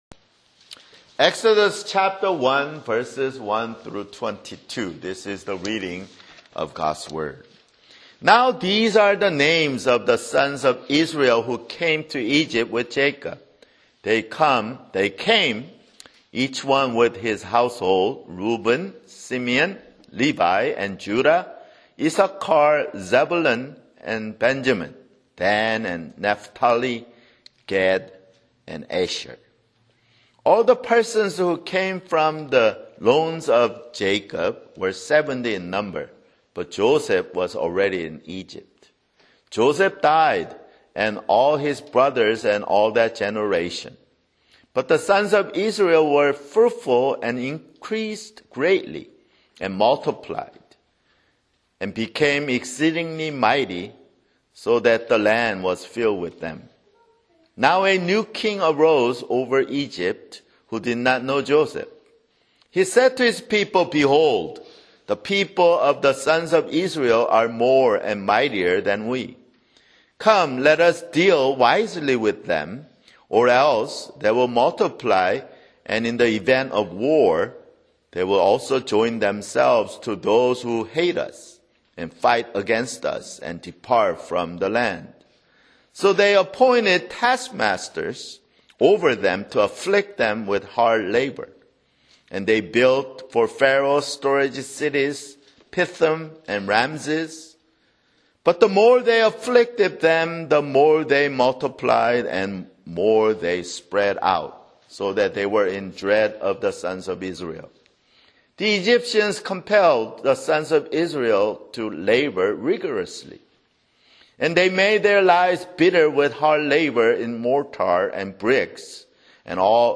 [Sermon] Exodus (2)